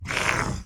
Roar1.ogg